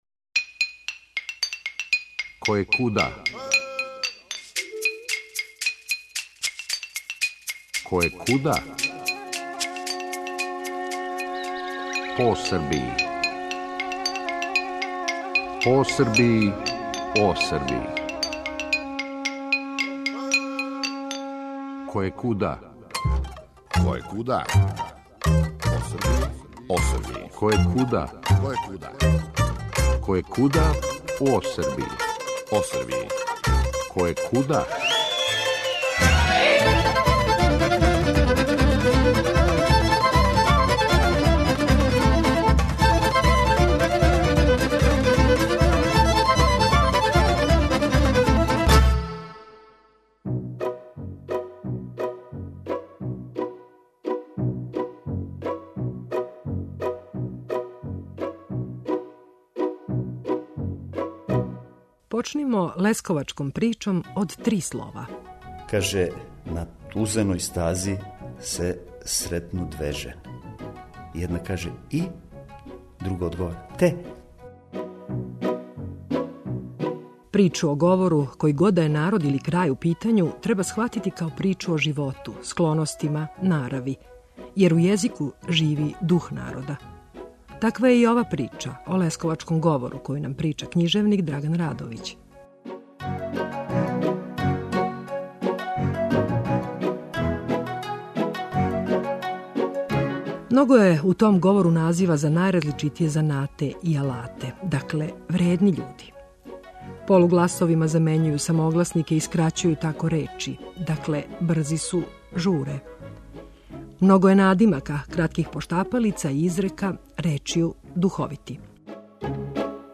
У емисији ћемо емитовати неколико кратких песама и духовитих забелешки, прочитаних лесковачким говором.